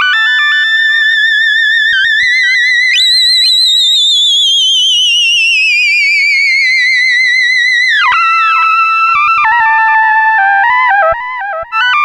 Synth 13.wav